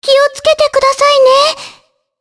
Shea-Vox_Skill1_jp.wav